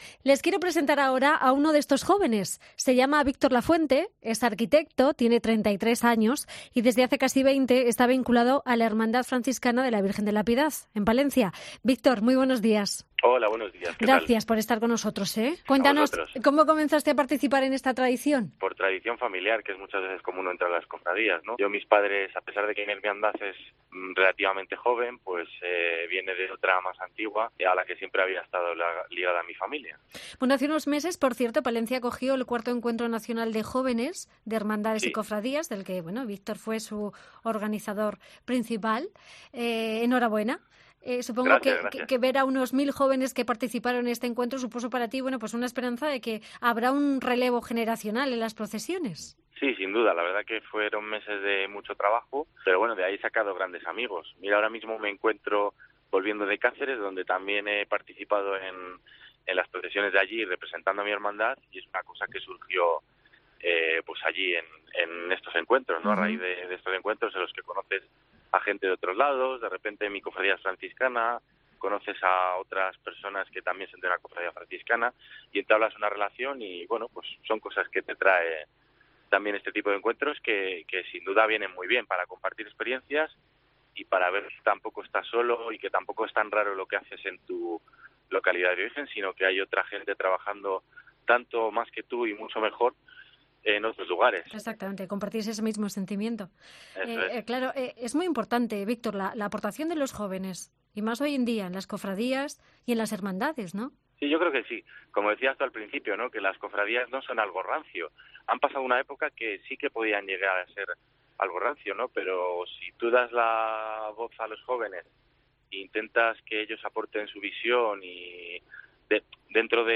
Este joven cofrade ha contado en 'Fin de Semana' que comenzó en esto por “tradición familiar”.